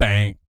BS BANG 05-R.wav